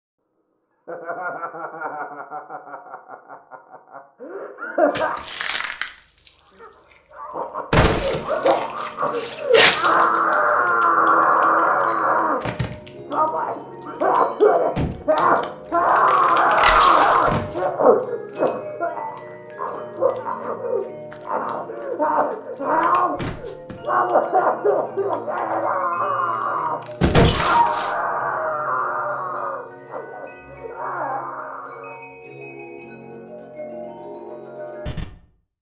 Звуки жуткие
Гибель существа, разрывающегося на части, из которого вылезает иное создание